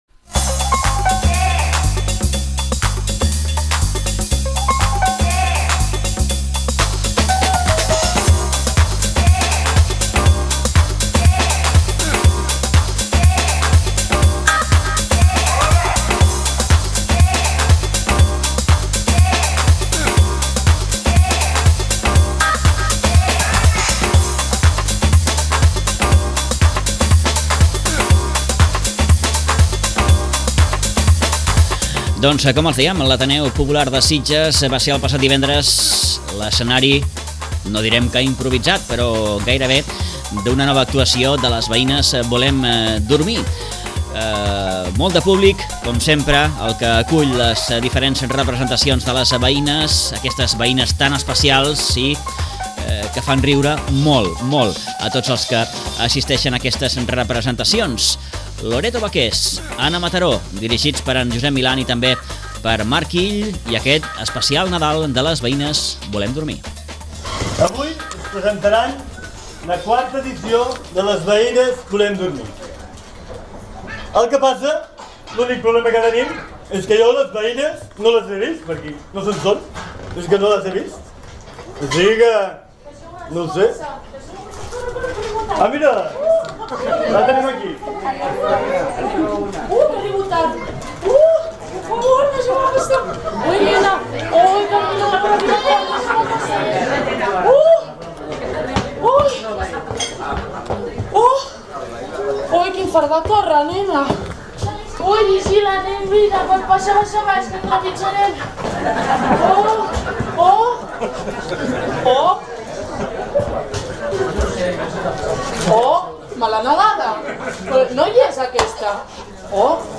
Us oferim integrament l’espectacle Les veïnes volem dormir, especial Nadal , que va tenir lloc el passat divendres a l’Ateneu de Sitges.